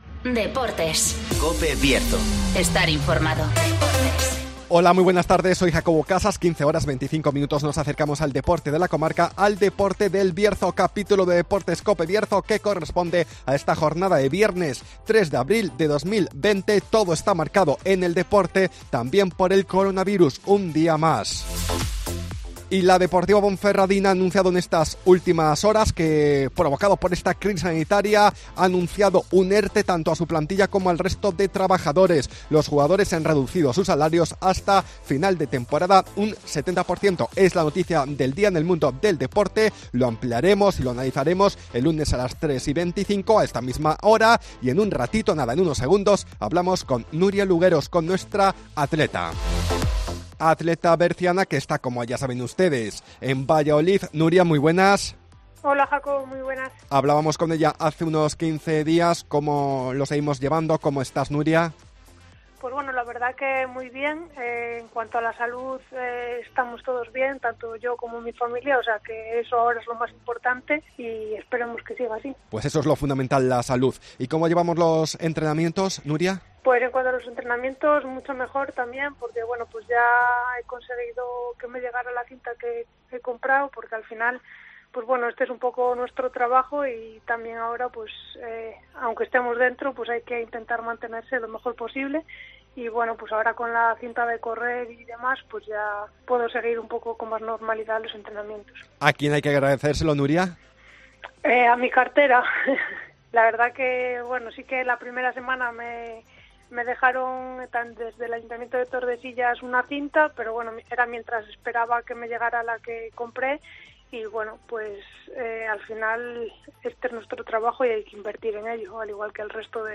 -Entrevista